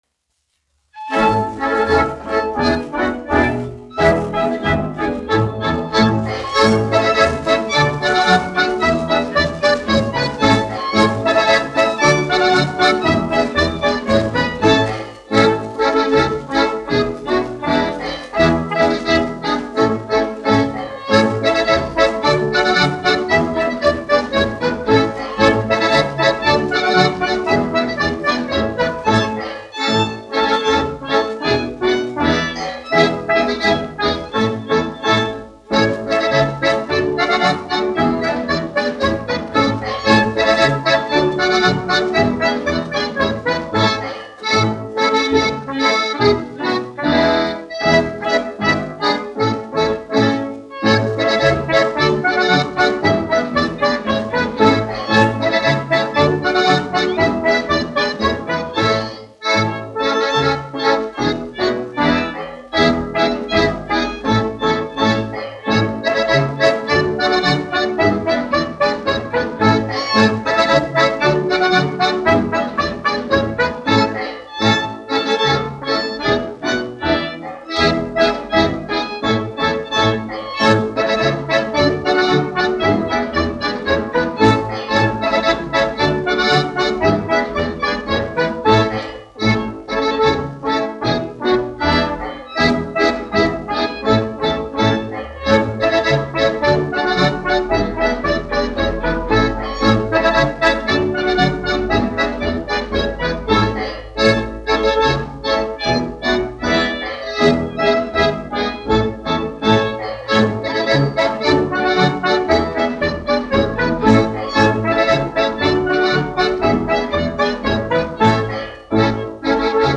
1 skpl. : analogs, 78 apgr/min, mono ; 25 cm
Latviešu tautas dejas
Skaņuplate